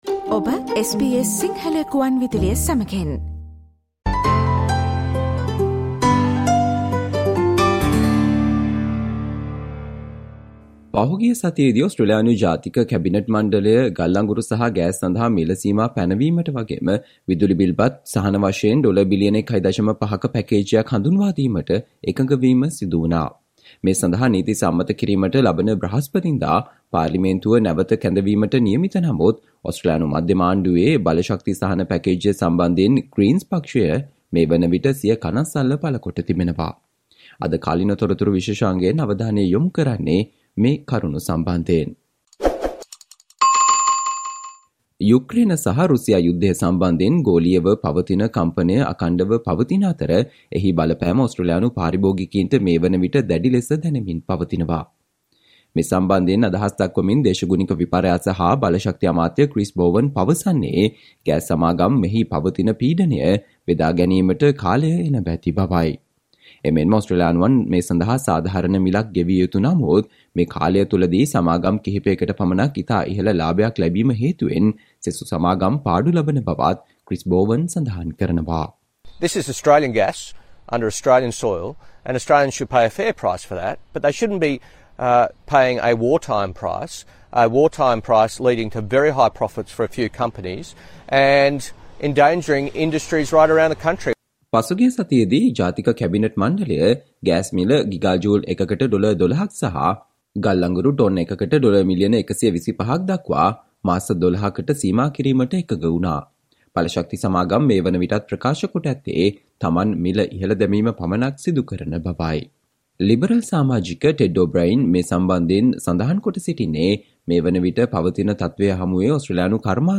Today -12 December, SBS Sinhala Radio current Affair Feature on $1.5 billion plan from government to relieve Australian's high energy bills